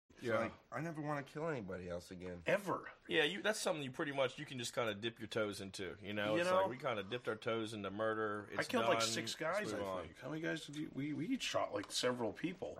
Shot Several People Sound Button - Free Download & Play